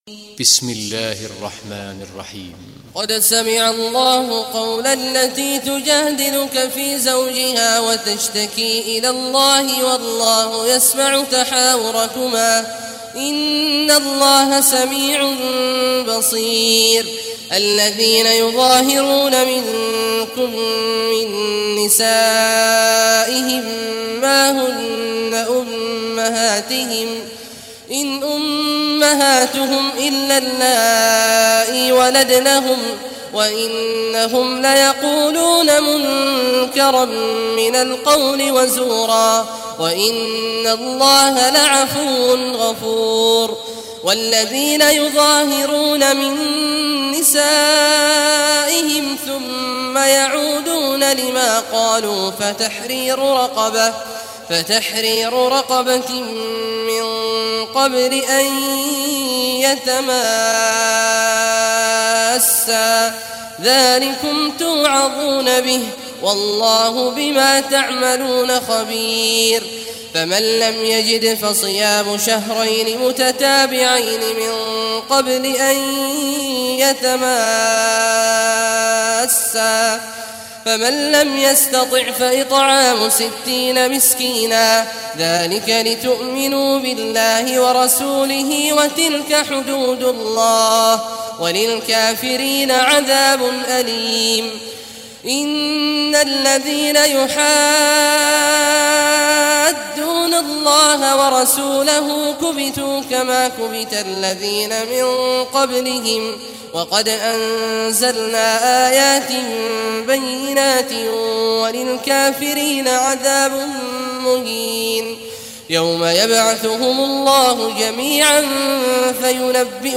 Surah Al-Mujadila Recitation by Sheikh Awad Juhany
Surah Al-Mujadila, listen or play online mp3 tilawat / recitation in Arabic in the beautiful voice of Sheikh Abdullah Awad al Juhany.